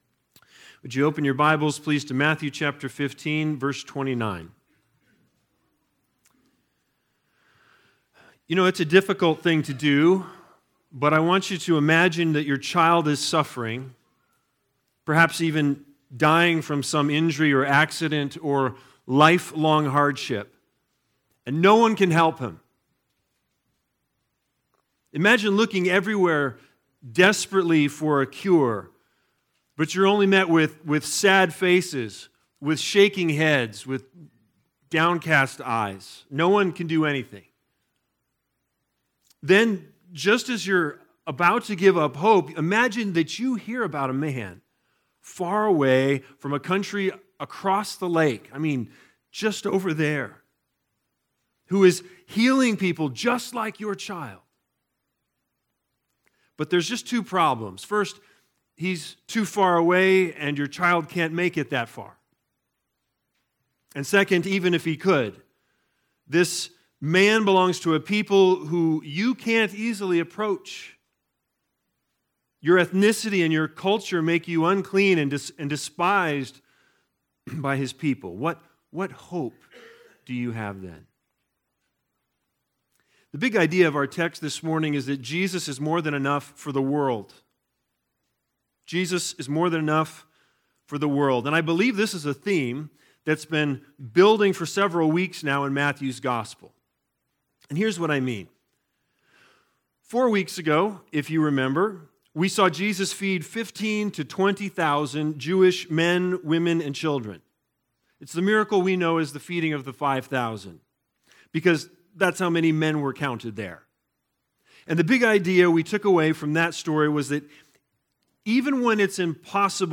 Matthew 15:29-39 Service Type: Sunday Sermons The Big Idea